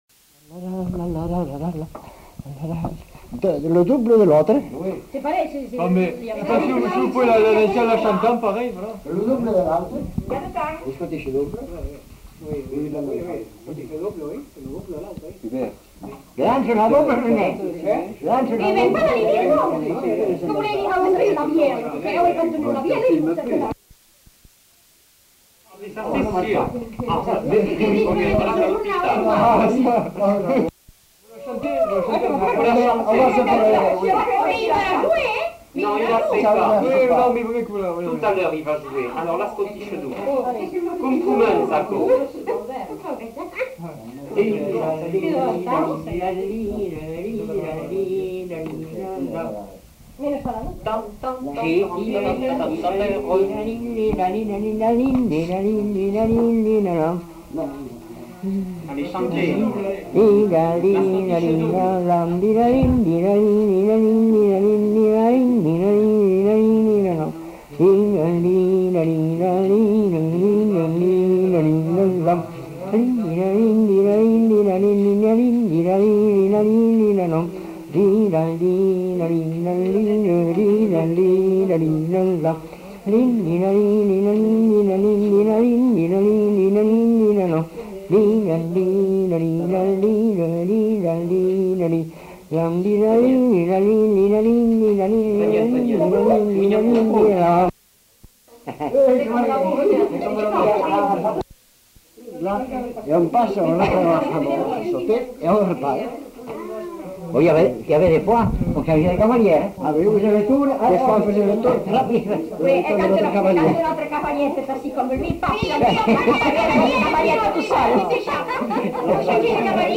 Aire culturelle : Grandes-Landes
Lieu : Luxey
Genre : chant
Effectif : 1
Type de voix : voix d'homme
Production du son : fredonné
Danse : scottish double